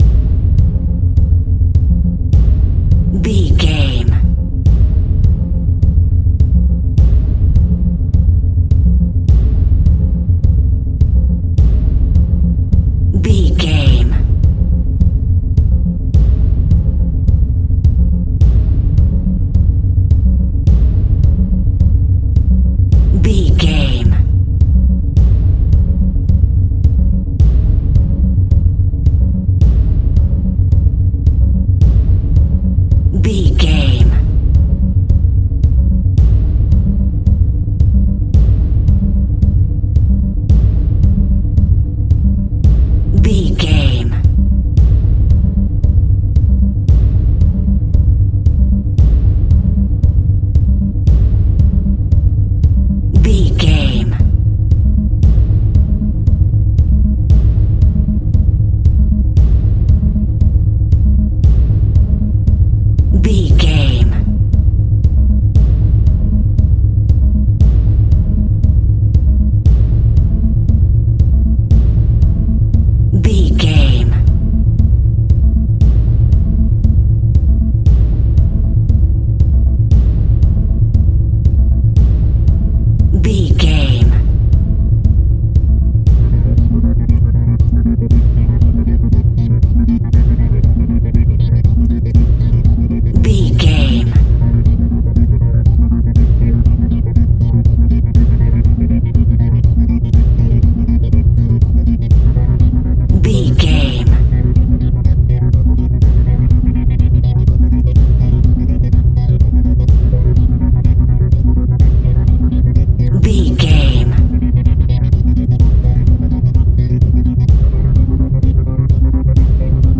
Tension Building.
In-crescendo
Thriller
Aeolian/Minor
Fast
ominous
haunting
eerie
synthesiser
percussion
drums